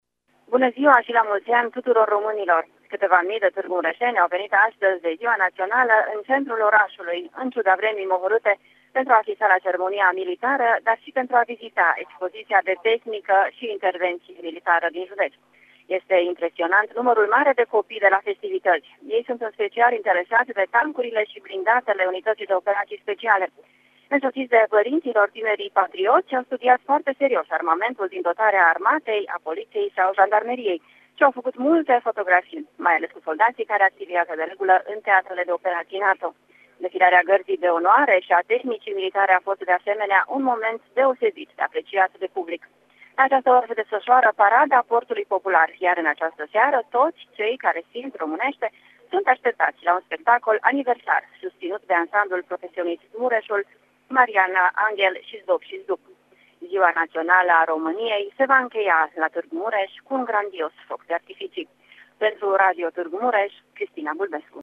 se află la fața locului și relatează: